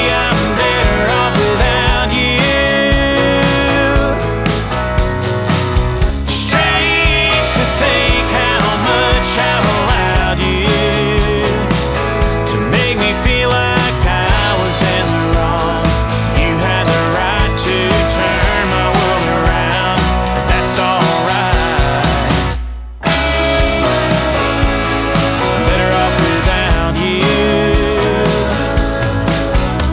• Country
The upbeat melody and rock-tinged guitars